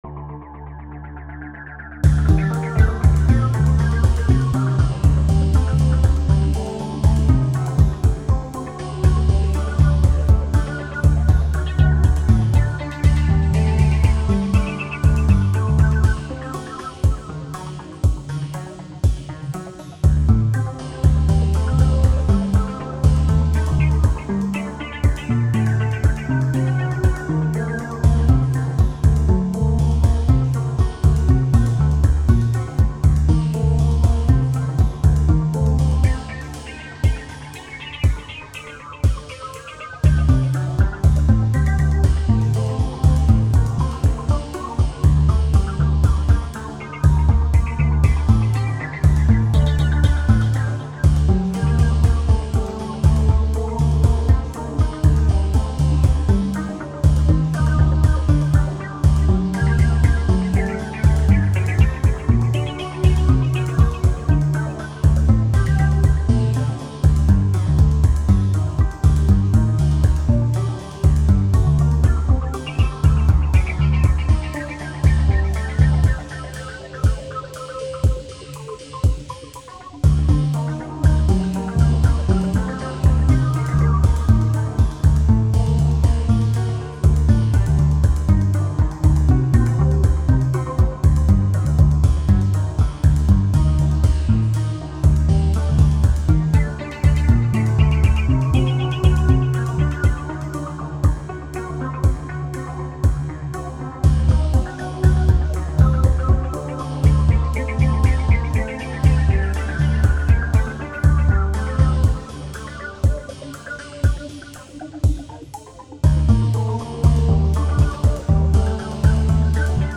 Dans la pratique j'en utilise actuellement 5 pour, les percussions, la basse et 3 instruments pouvant être rythmiques ou mélodiques.
Voici 3 ambiances musicales pour illustrer les capacités de la Société Henon.